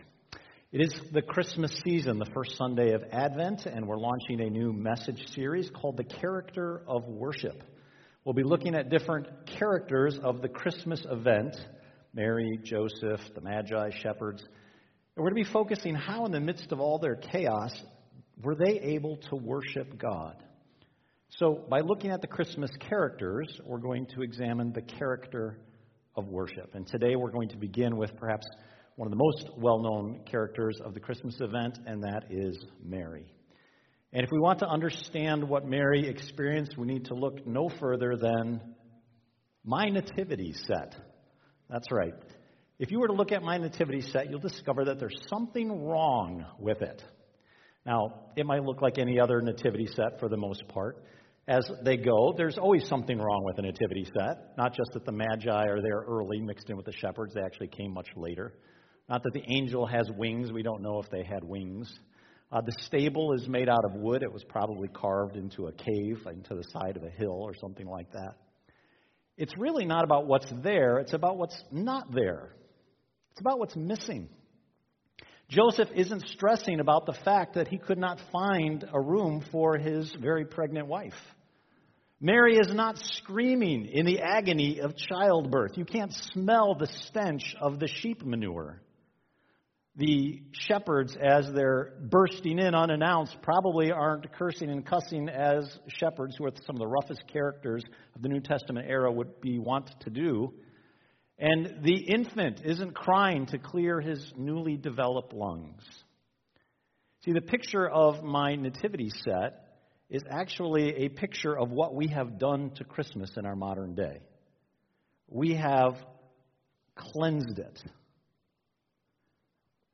The Character of Worship Service Type: Sunday Morning « Growing in Gratitude for What God Does NOT Do Silent Life